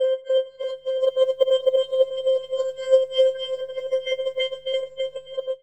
Index of /90_sSampleCDs/Chillout (ambient1&2)/09 Flutterings (pad)
Amb1n2_l_flutter_c.wav